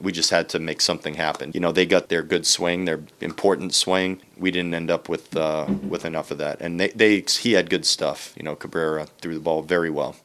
Twins manager Rocco Baldelli says the offense never got anything going against good Miami pitching,